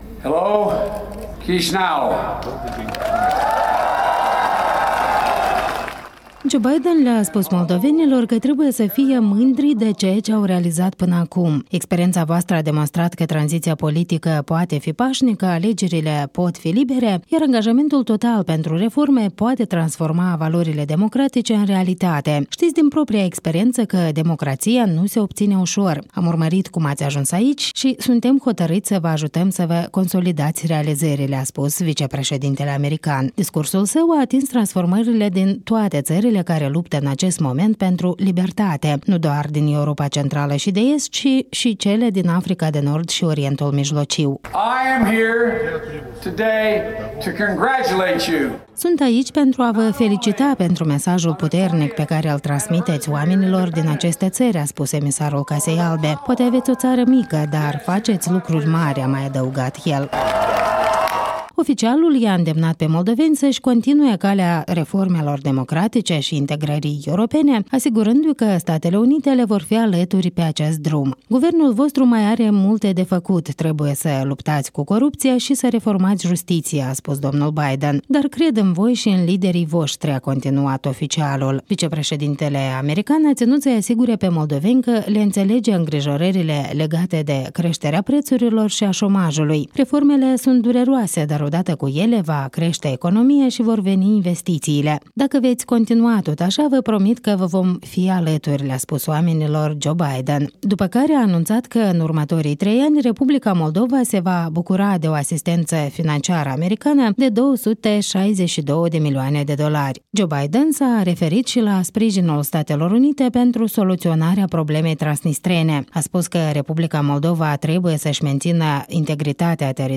Vice-președintele american Joe Biden se adresează mulțimii din scuarul Operei
Vicepreşedintele american Joseph Biden a ţinut în după-amiaza zilei un discurs public în scuarul Operei, în faţa mai multor mii de moldoveni.